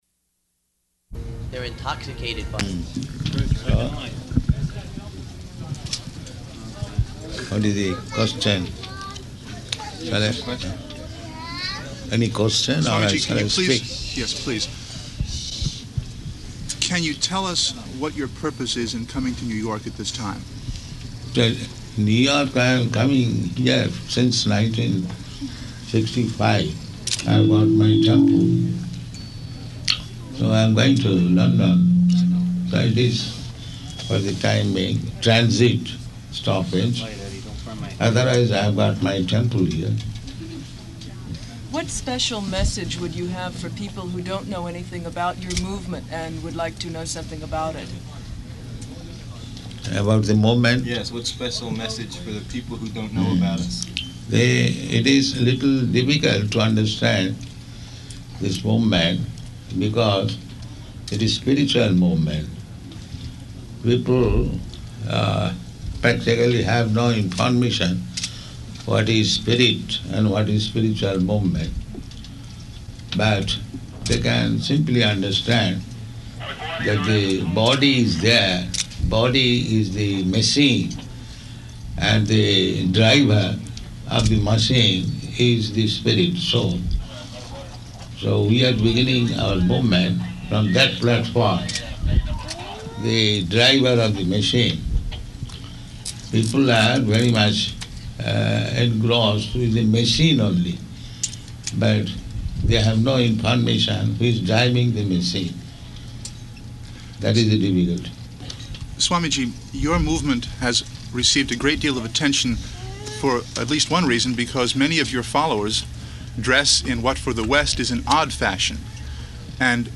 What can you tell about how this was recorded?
-- Type: Interview Dated: March 5th 1975 Location: New York Audio file